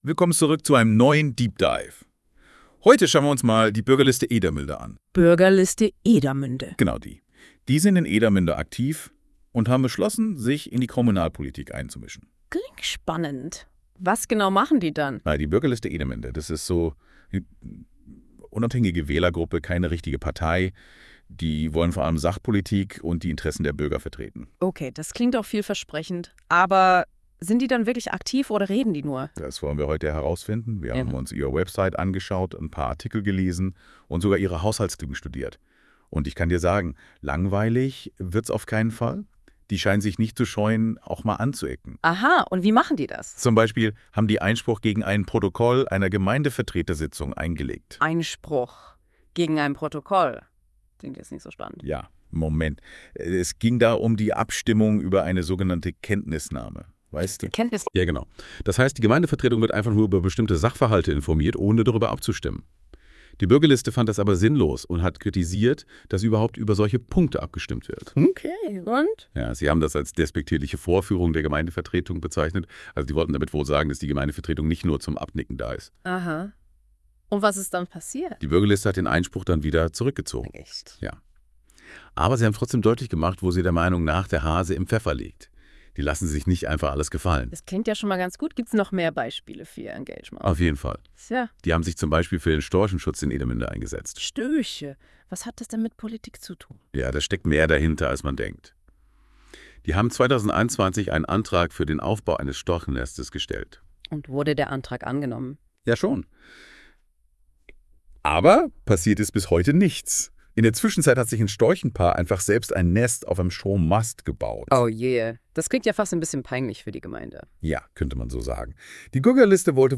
Details Kategorie: Podcast Podcast über die Bürgerliste Edermünde Hinweis: Dieser Podcast wurde mit Hilfe einer künstlichen Intelligenz erzeugt.